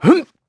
Siegfried-Vox_Jump.wav